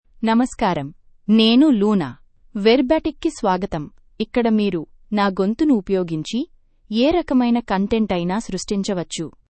Luna — Female Telugu (India) AI Voice | TTS, Voice Cloning & Video | Verbatik AI
Voice sample
Listen to Luna's female Telugu voice.
Female
Luna delivers clear pronunciation with authentic India Telugu intonation, making your content sound professionally produced.